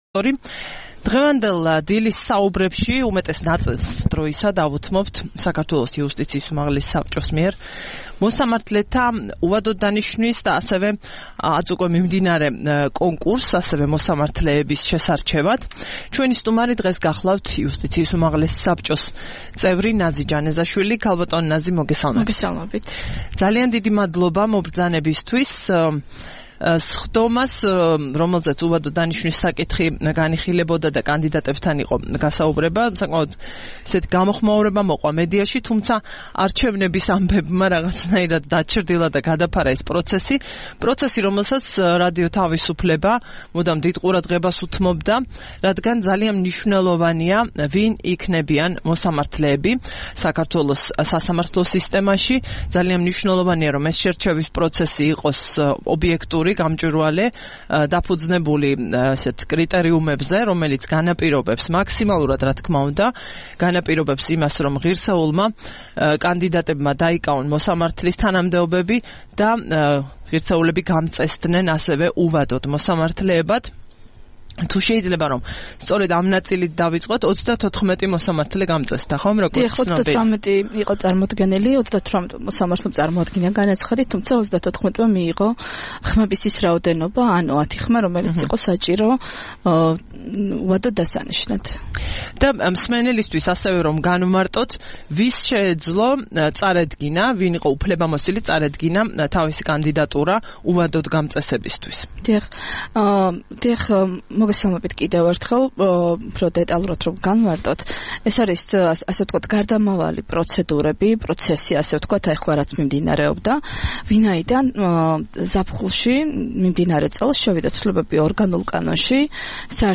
სტუმრად ჩვენს ეთერში: ნაზი ჯანეზაშვილი
25 ოქტომბერს რადიო თავისუფლების "დილის საუბრების" სტუმარი იყო ნაზი ჯანეზაშვილი, იუსტიციის უმაღლესი საბჭოს წევრი.